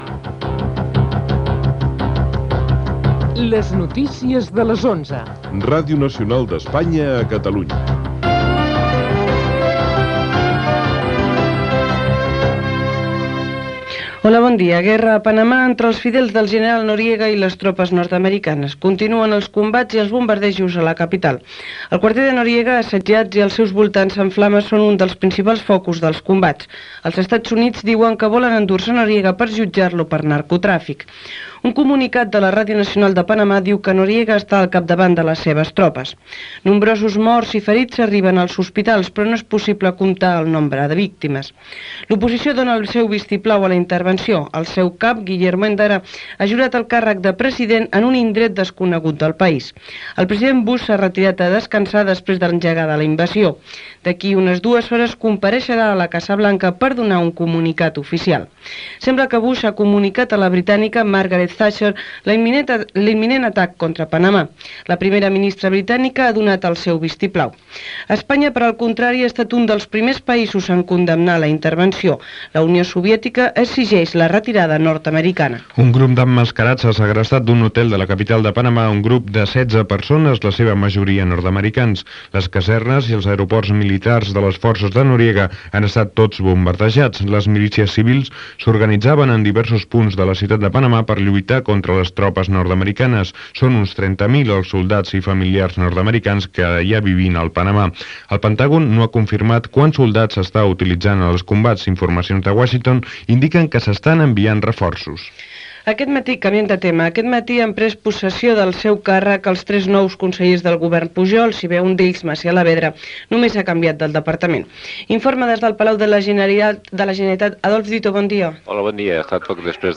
Careta del programa, guerra a Panamà, possessió del càrrec de tres nous Consellers de la Generalitat, judici a Lleida, assassinat del Grapo a El Prat de Llobregat
Informatiu